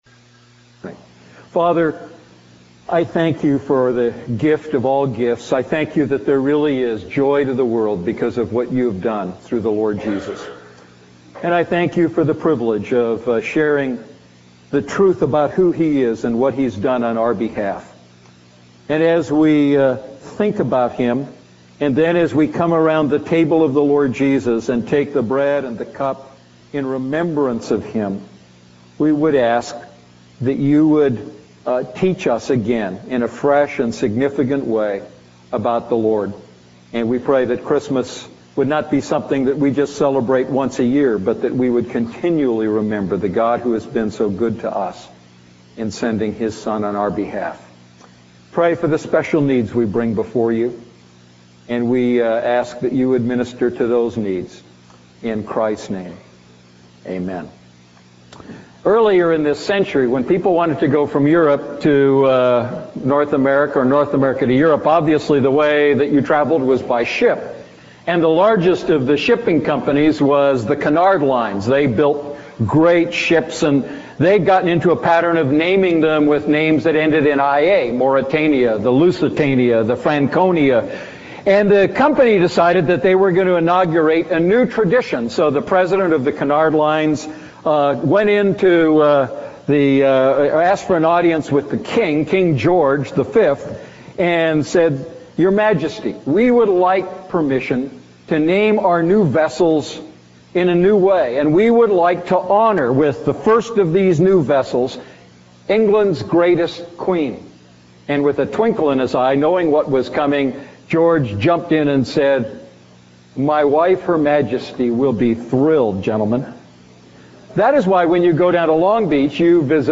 A message from the series "Miscellaneous."